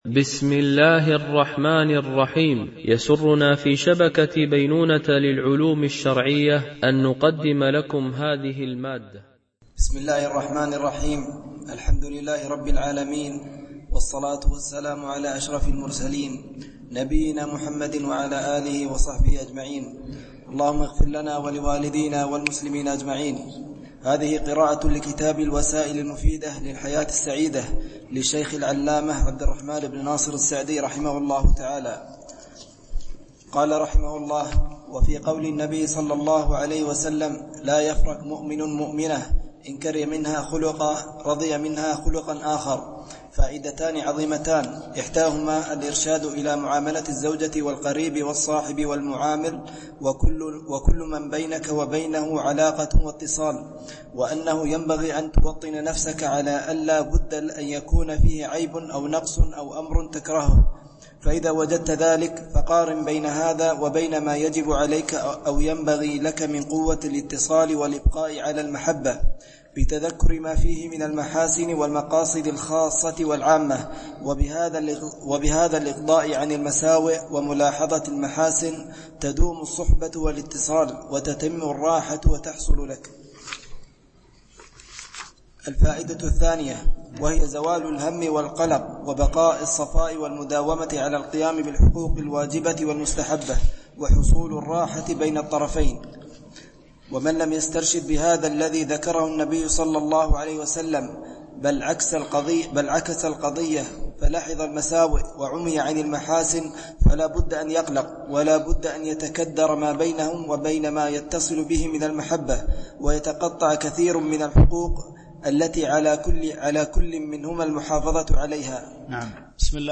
دورة علمية شرعية، بمسجد أم المؤمنين عائشة - دبي (القوز 4)